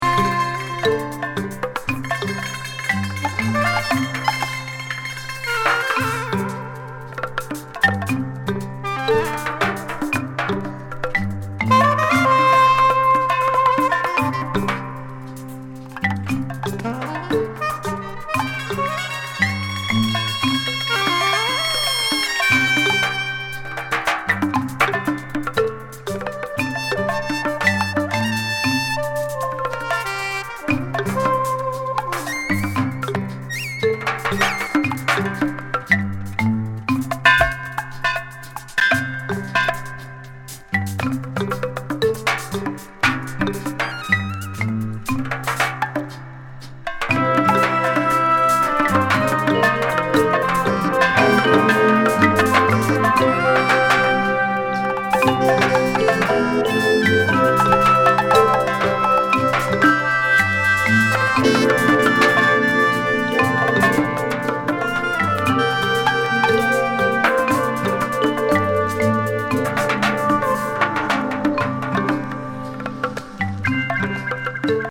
イタリアン・コンポーザー兼パーカッション奏者の晴天地中海ジャズ・ロックなソロ77年作。